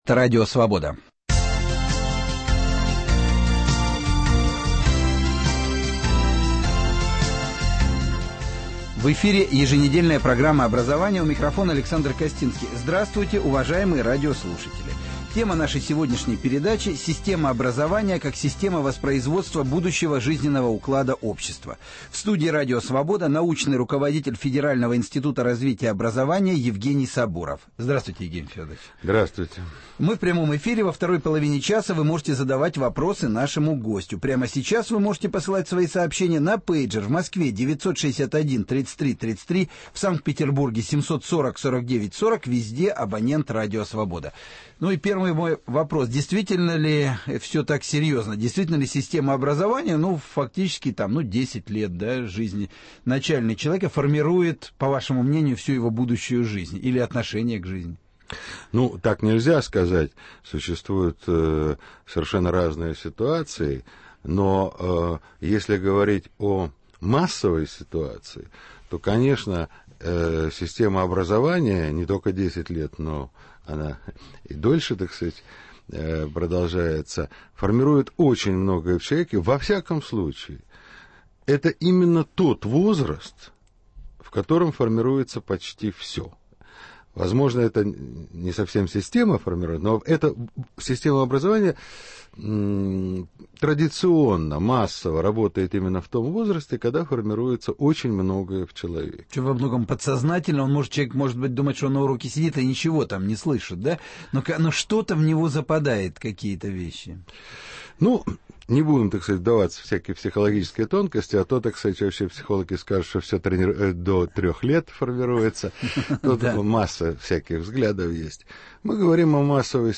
Система образования, как система воспроизводства будущего жизненного уклада общества. Гость студии: научный руководитель федерального Института развития образования Евгений Сабуров